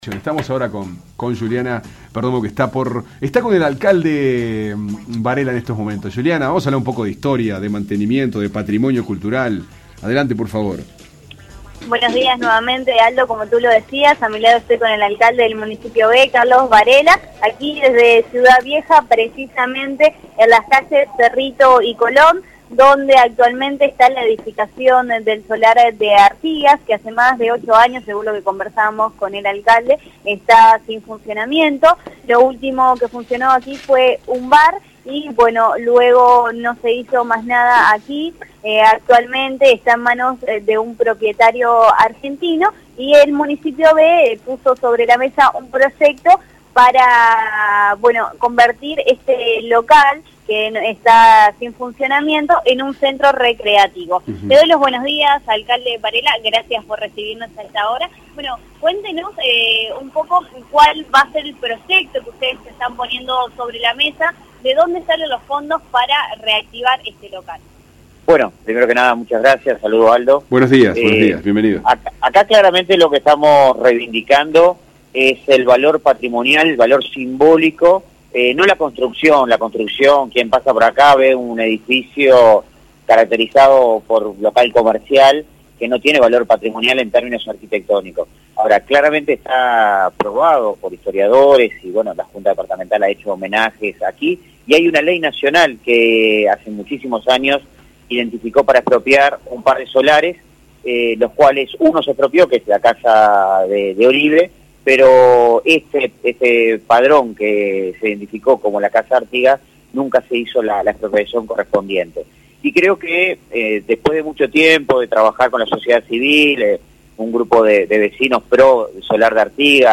Fuentes Confiables entrevistó al Alcalde Varela que parece querer liderar una propuesta de futuro.